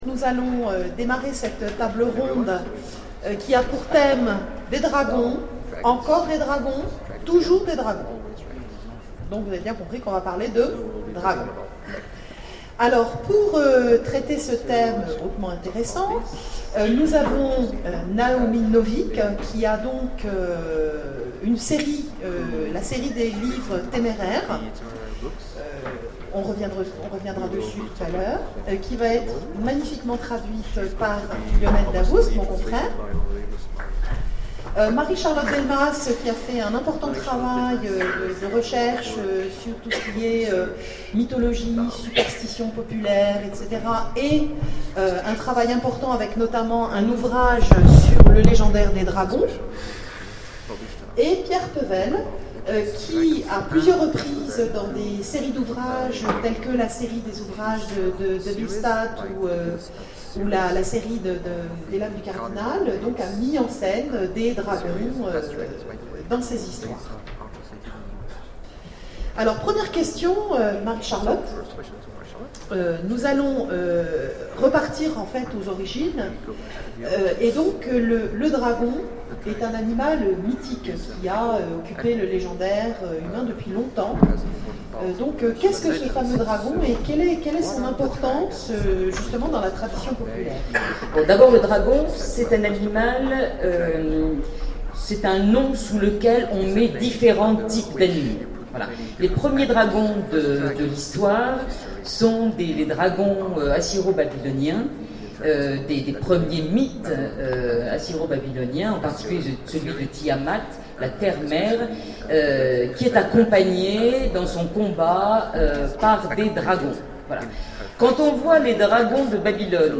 Imaginales 2012 : Conférence encore des Dragons